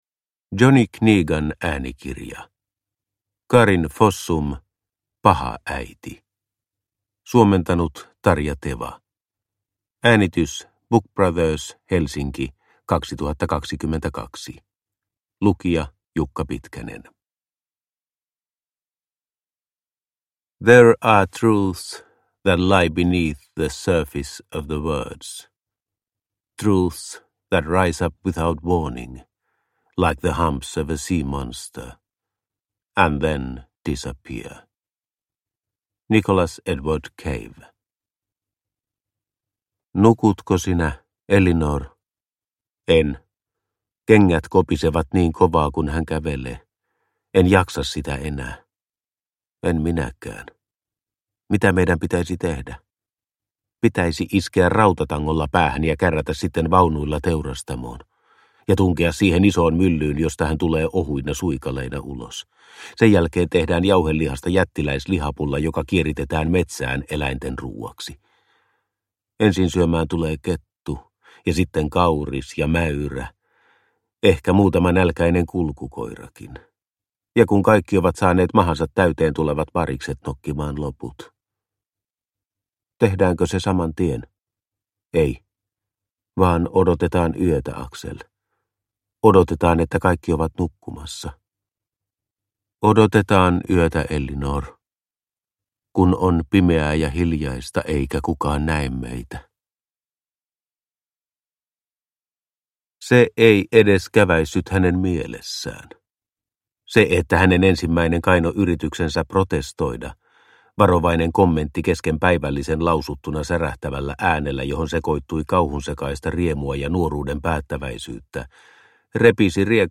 Paha äiti – Ljudbok – Laddas ner